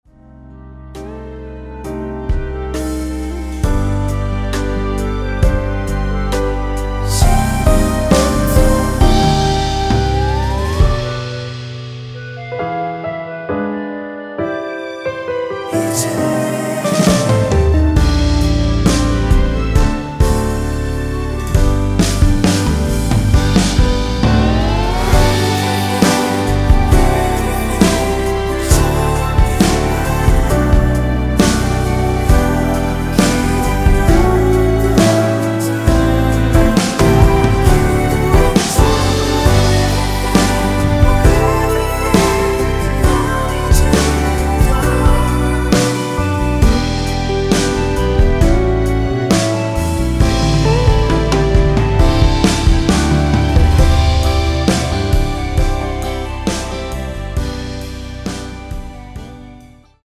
원키 멜로디와 코러스 포함된 MR입니다.(미리듣기 확인)
앞부분30초, 뒷부분30초씩 편집해서 올려 드리고 있습니다.
중간에 음이 끈어지고 다시 나오는 이유는